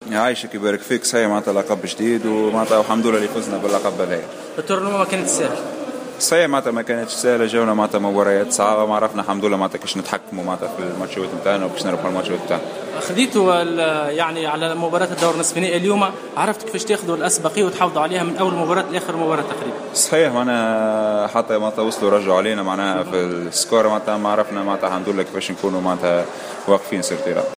وائل جلوز يتوج مع برشلونة بكأس العالم لكرة اليد (تصريح صوتي)